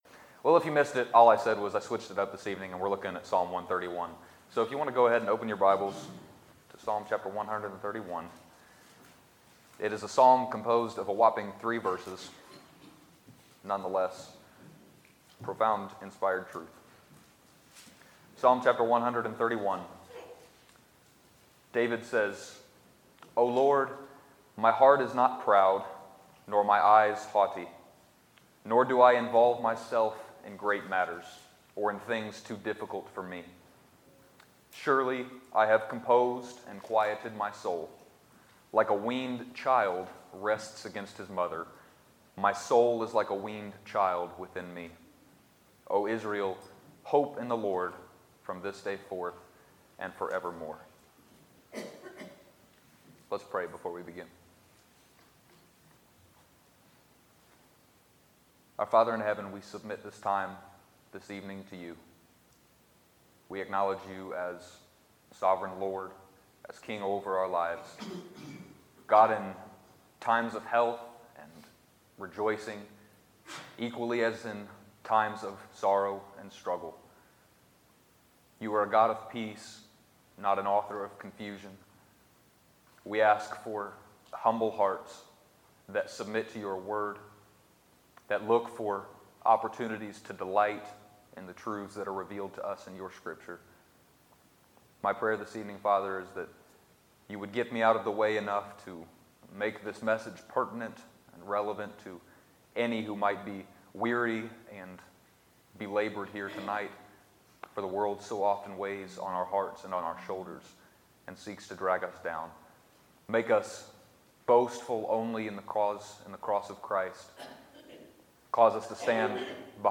Sermons, December 17, 2017